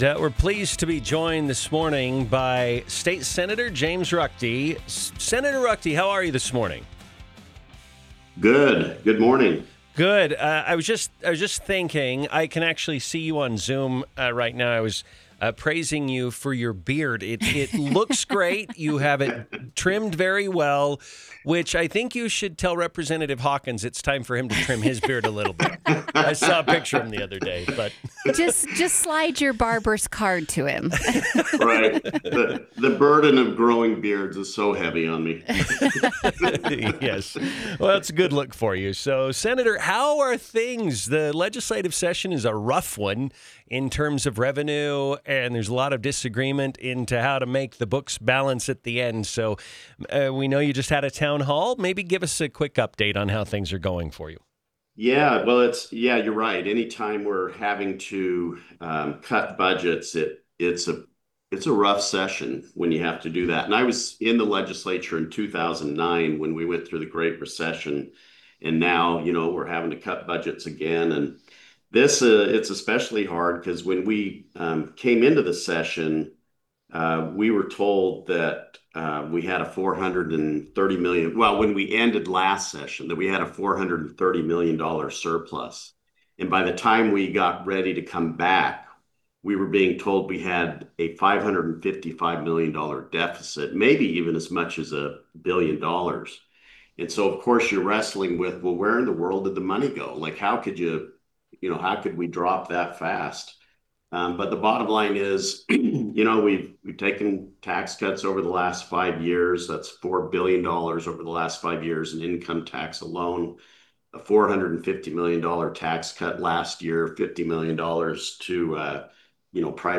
INTERVIEW: Sen. James Ruchti on budget, HB93, Tim Walz headline Dem Event - Newstalk 107.9